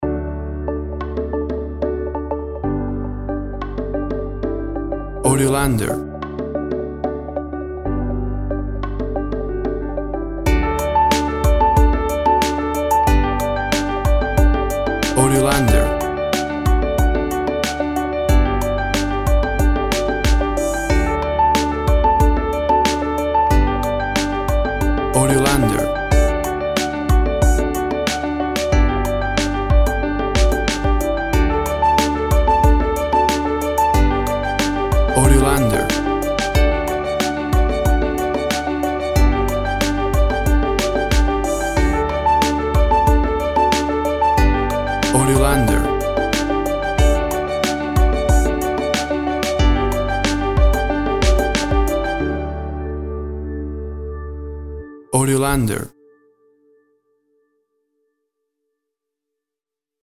Tempo (BPM) 92